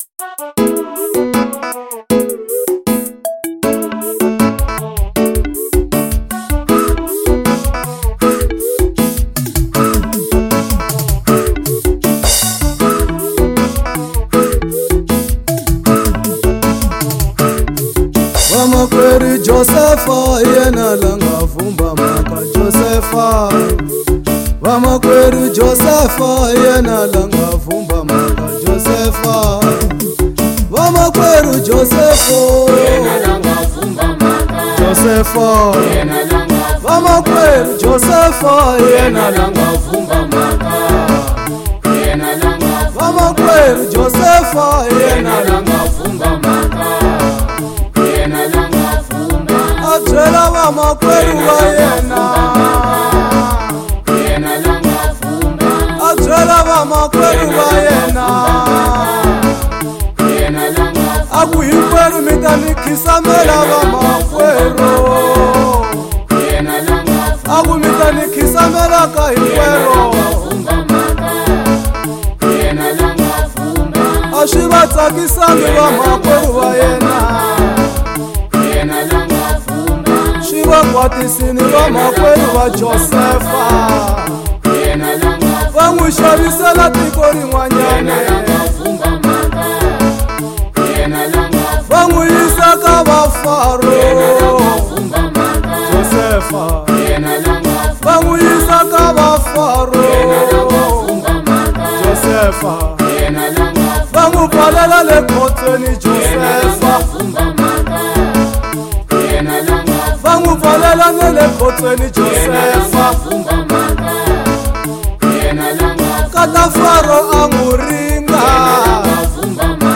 08:00 Genre : Gospel Size